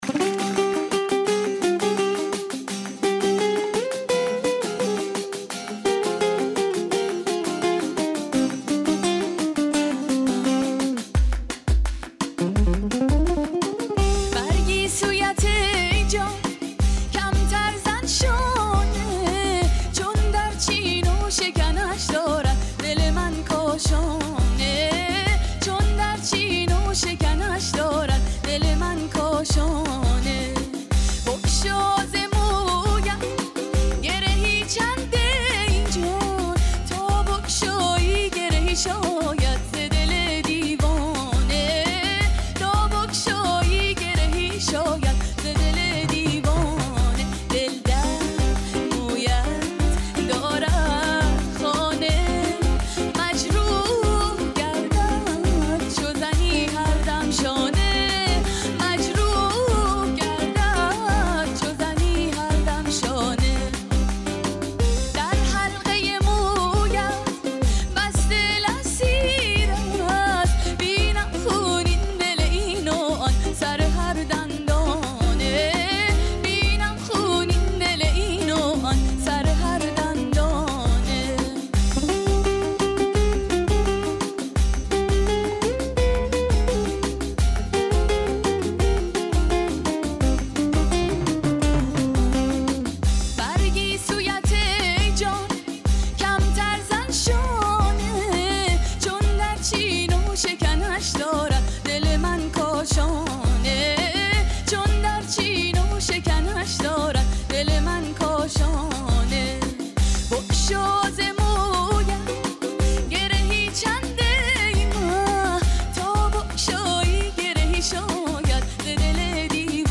با صدای زن 2